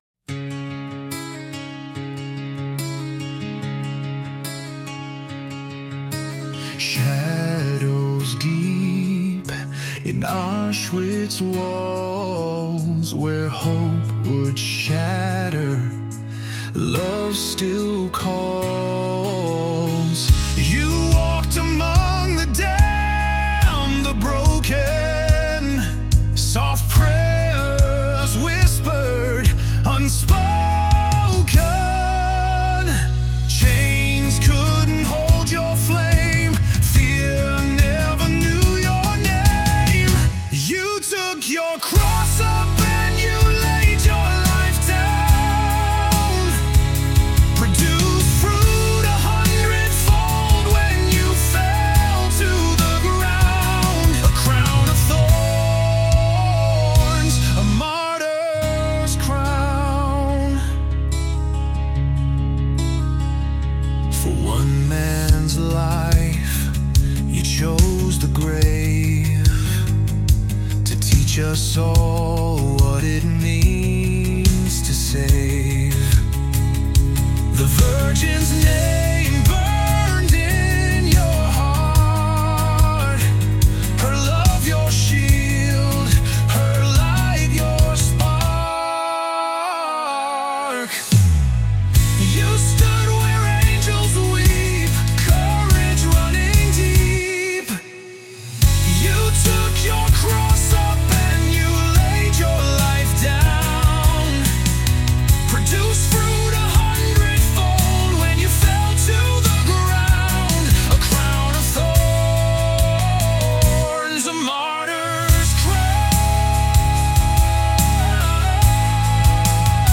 Rock song about St. Maximilian Kolbe